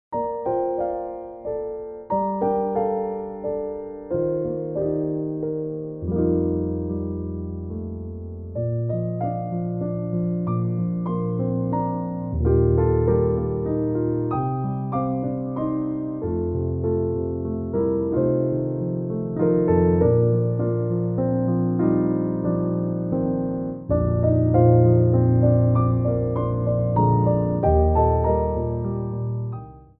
Adage
3/4 (8x8)